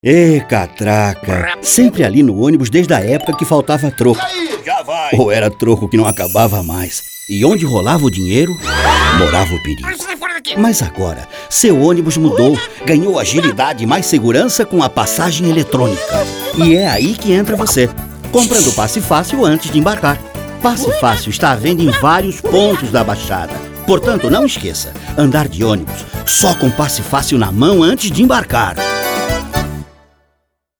Sua voz é uma das mais versáteis da dublagem brasileira, pois ele possui quatro ou mais tipos de vozes diferentes, desde a fanhosa até seu famoso vozeirão profundo.